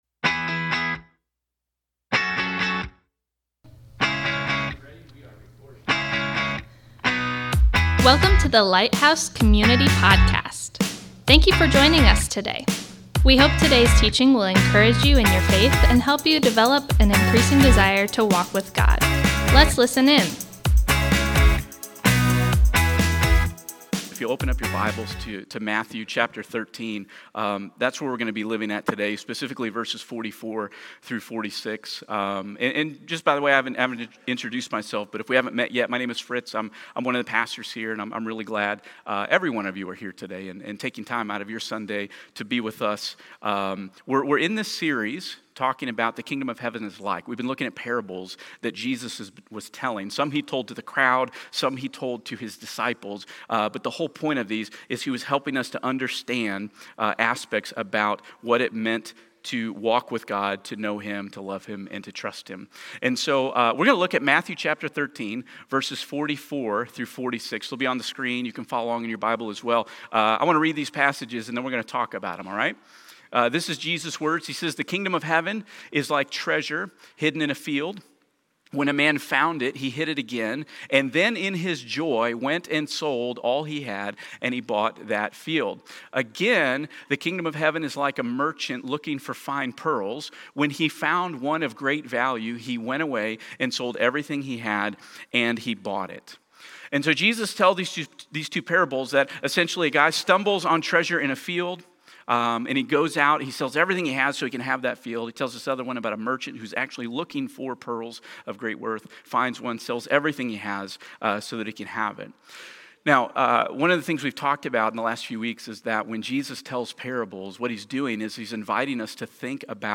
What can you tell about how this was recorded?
Thank you for joining us today as we come together to worship!